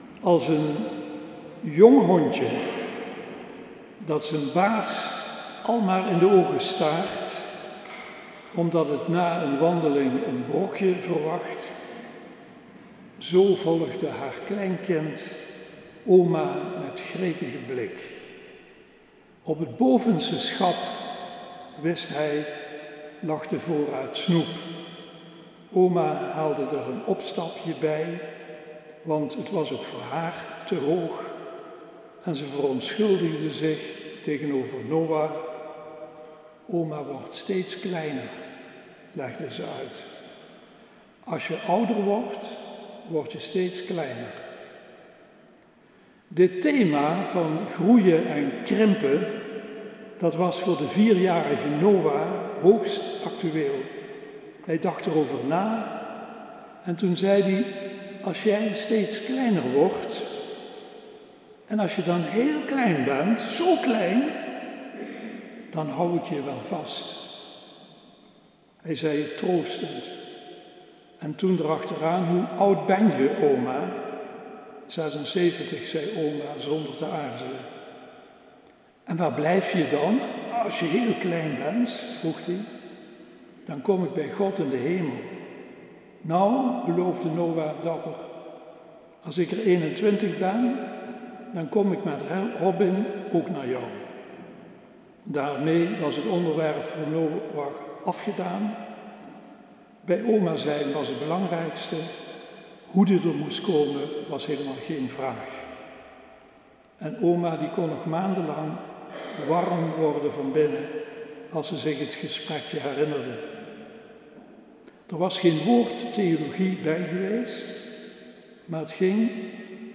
preek 2 nov 2019 - allerheiligen.mp3